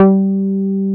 R MOOG G4MP.wav